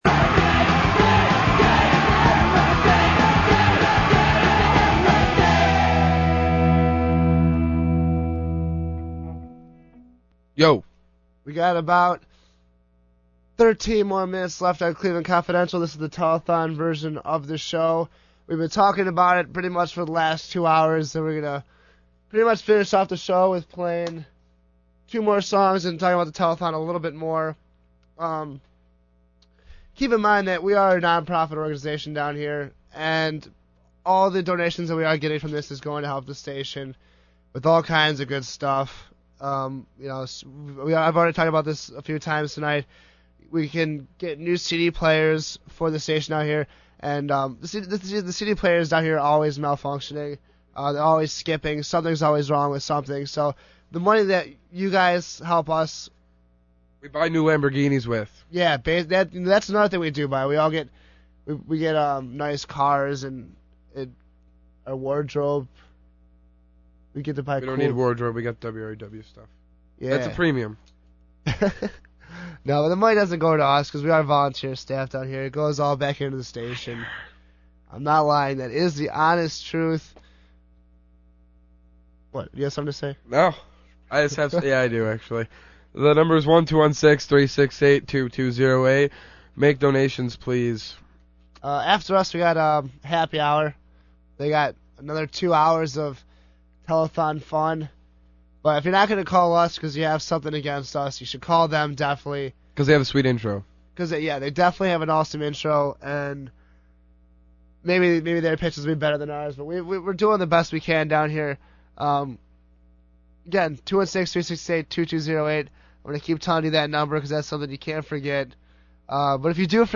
• Listen to how positive and upbeat each programmer is when talking about the fundraiser.